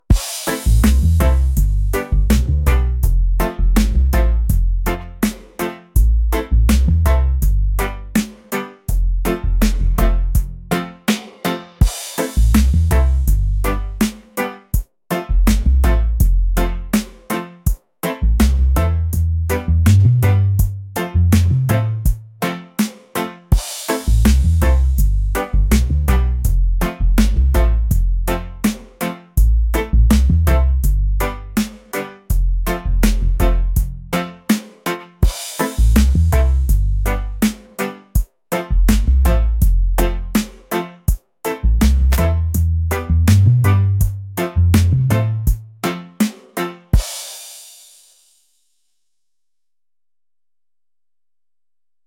reggae | relaxed | laid-back